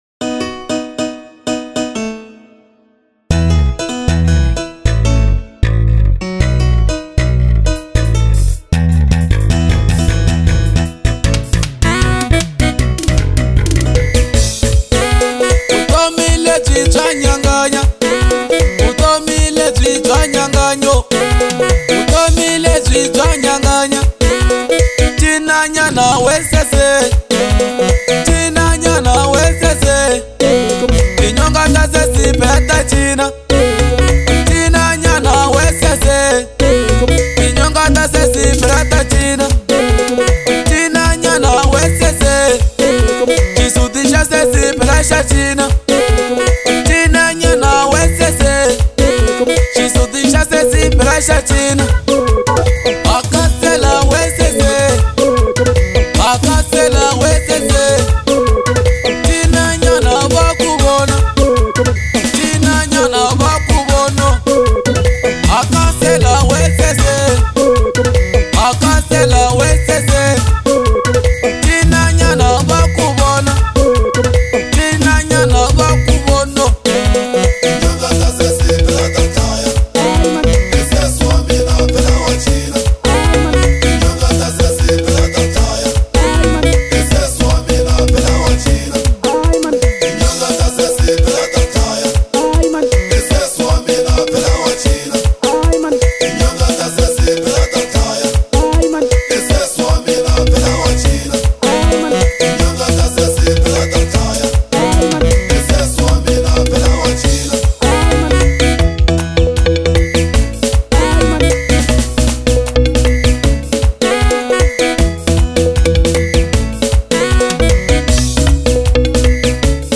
04:58 Genre : Xitsonga Size